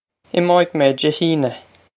Im-oh-ig may Jay hee-on-a.
This is an approximate phonetic pronunciation of the phrase.